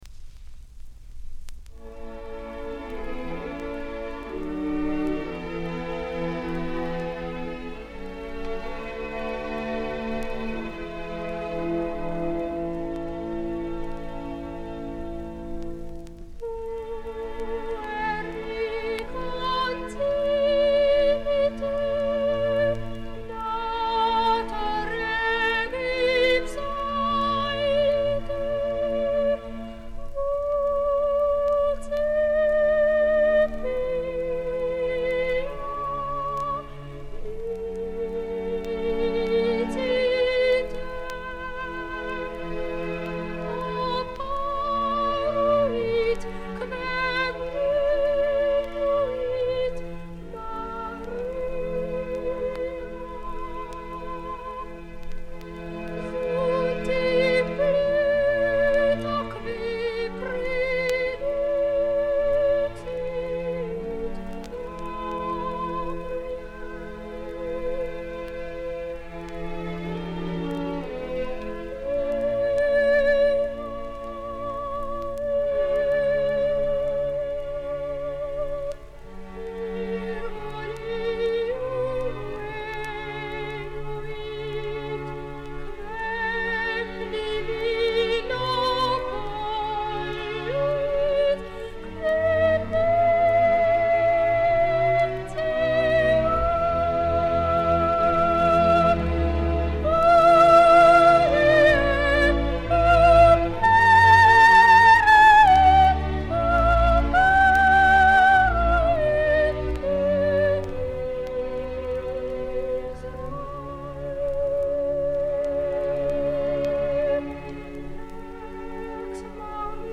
hochromantische
highly romantic